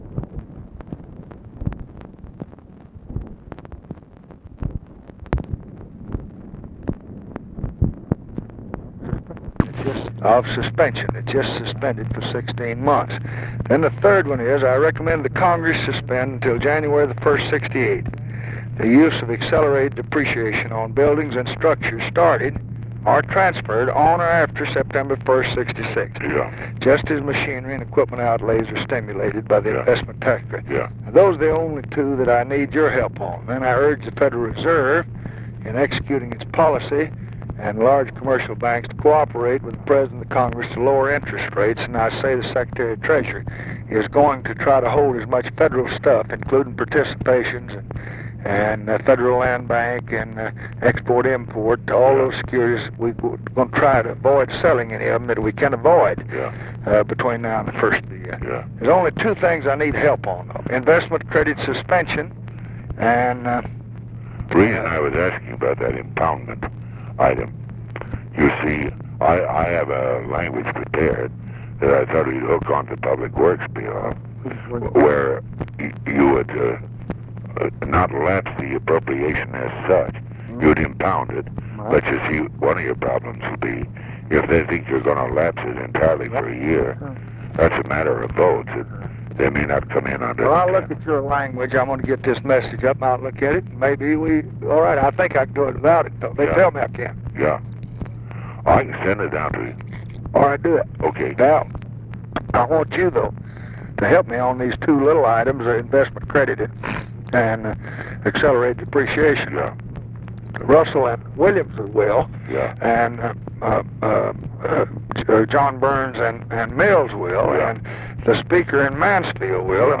Conversation with EVERETT DIRKSEN, September 8, 1966
Secret White House Tapes